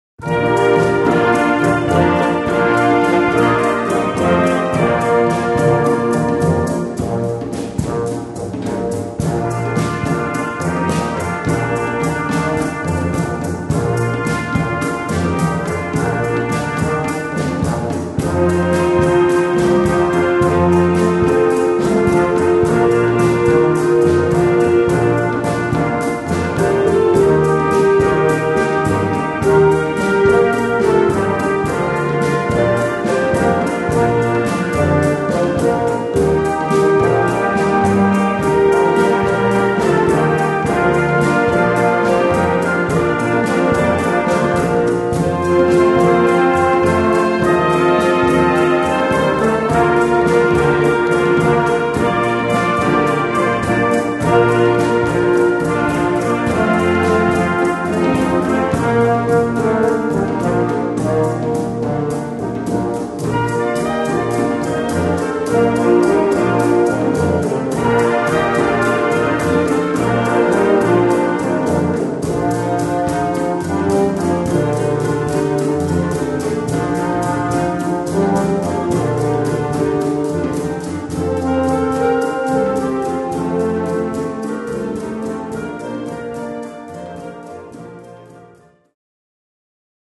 Partitions pour orchestre d'harmonie, ou - fanfare jeunes.
• Douce nuit avec une touche africaine.